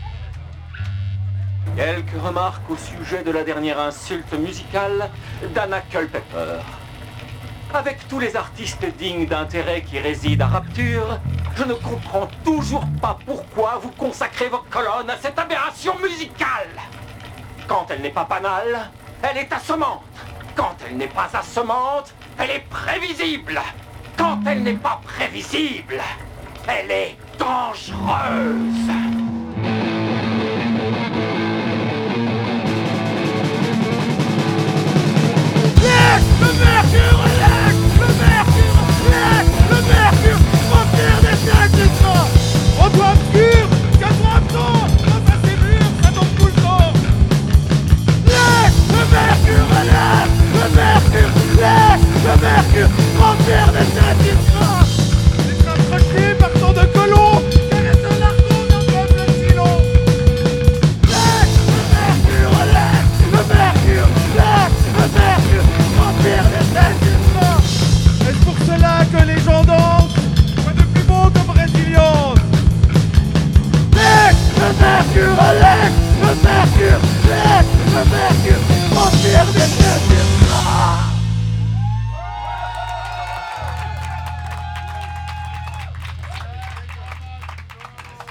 sombre meta-metal de l’abîme joyeux